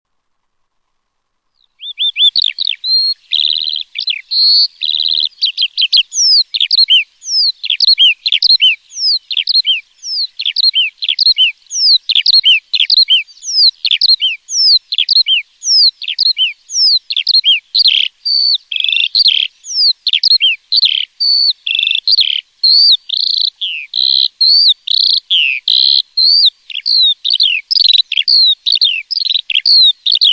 Tierstimme:
Gesang der Feldlerche,
Die männlichen Feldlerchen fliegen zur Abgrenzung des Brutreviers oft über 100 m hoch und singen im anhaltenden Rüttelflug bis zu 15 min.
01 - Feldlerche.mp3